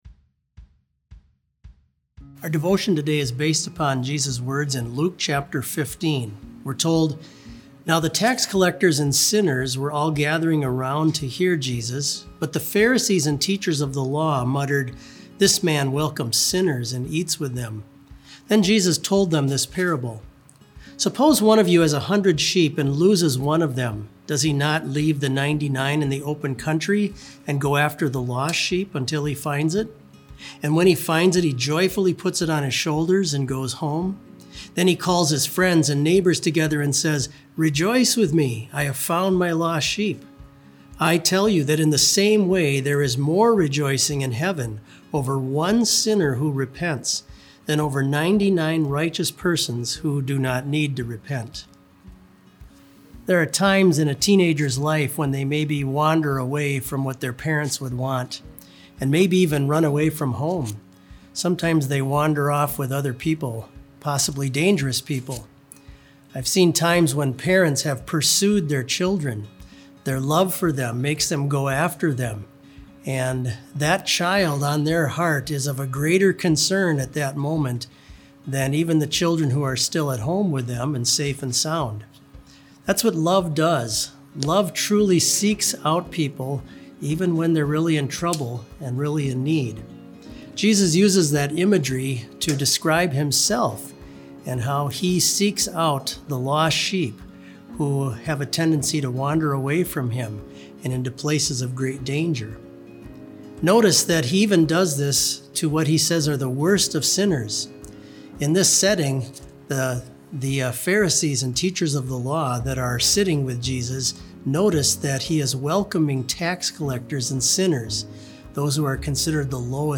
Complete service audio for BLC Devotion - April 30, 2020